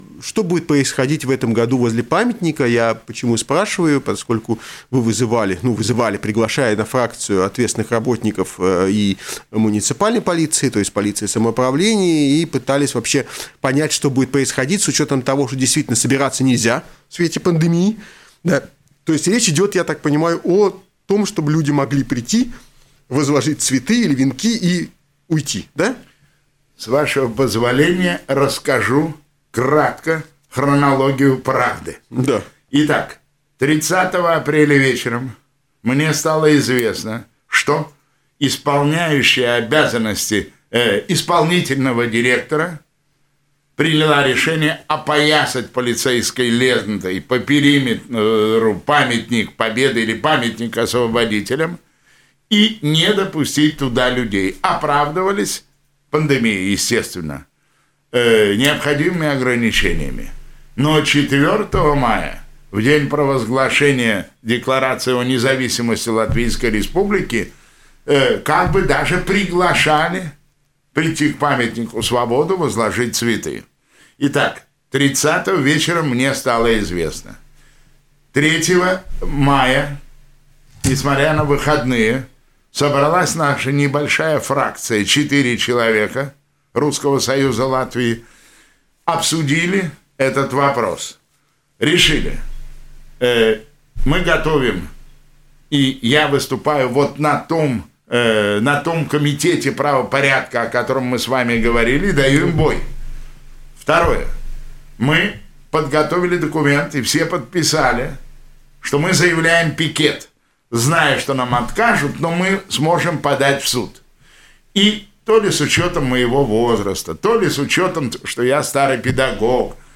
Депутат Рижской думы от фракции «Русский союз Латвии» Яков Плинер рассказал в эфире радио Baltkom о хронологии утверждения мероприятия у Памятника освободителям Риги 9 мая в этом году.